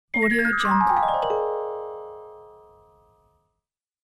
دانلود افکت صوتی نزول به مرحله قبل، بسته شدن حباب رؤیا 3